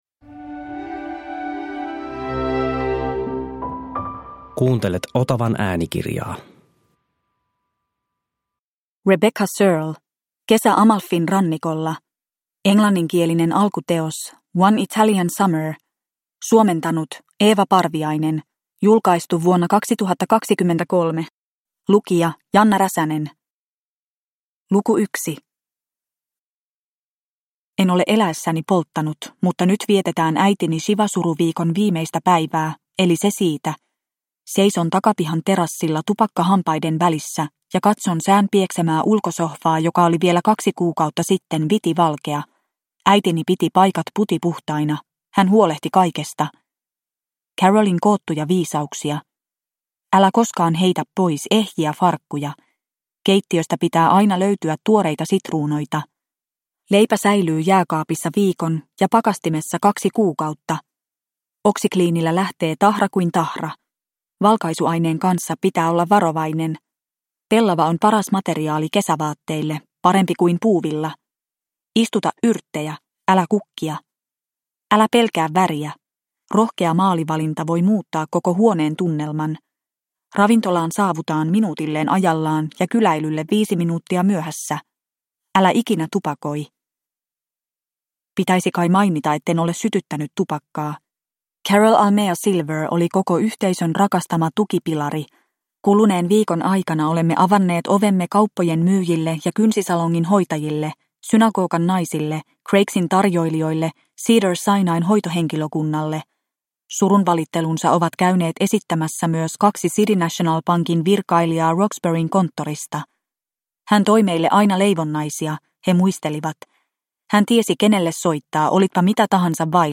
Kesä Amalfin rannikolla – Ljudbok – Laddas ner